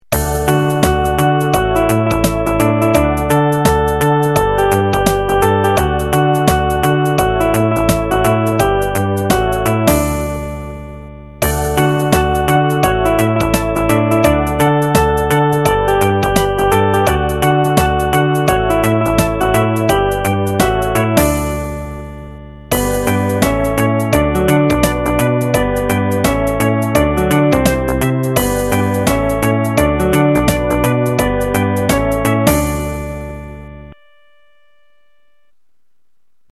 • Music has an ending (Doesn't loop)